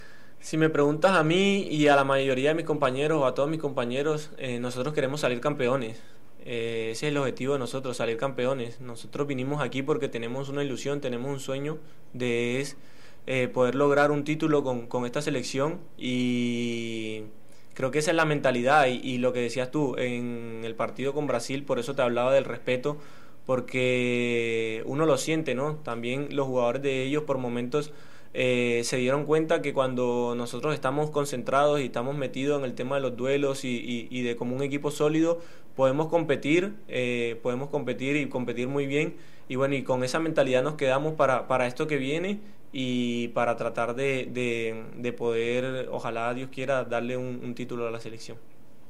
(Rafael Santos Borré en rueda de prensa)